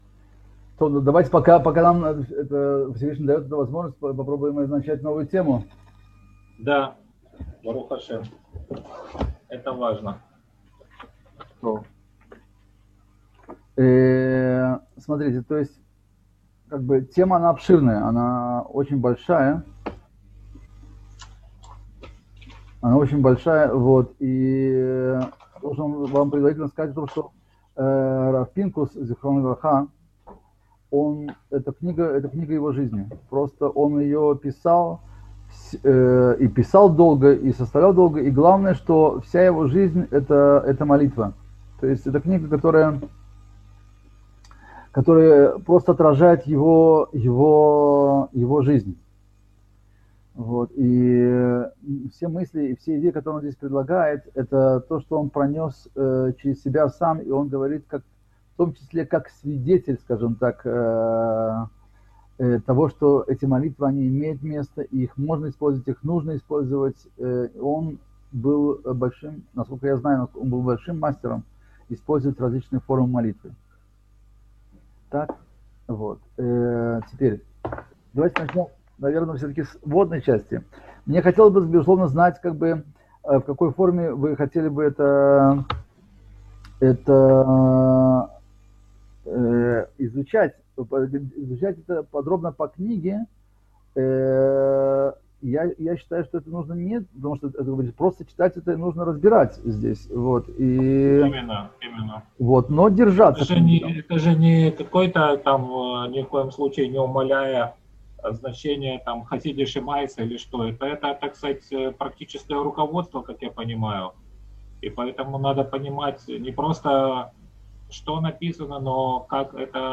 Цикл уроков по недавно вышедшей в свет книге рава Шимшона Давида Пинкуса «Врата в мир молитвы»